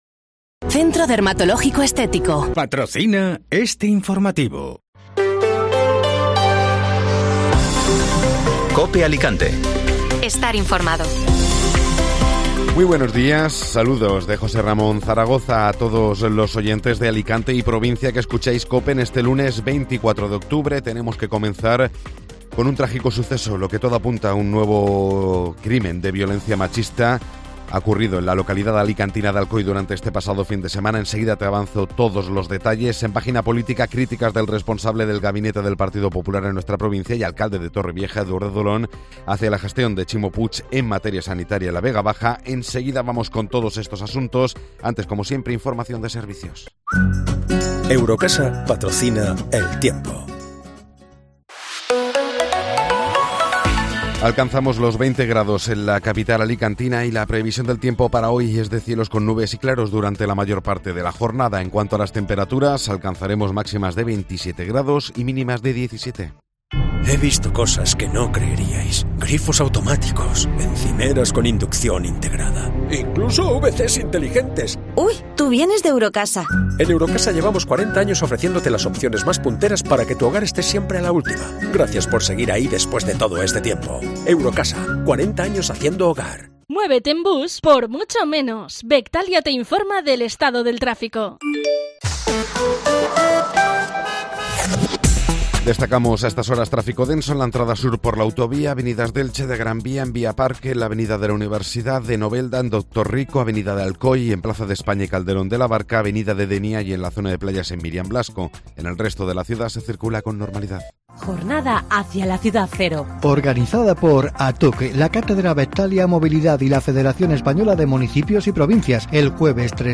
Informativo Matinal (Lunes 24 de Octubre)